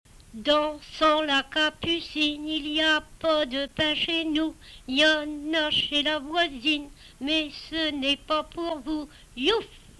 Emplacement Miquelon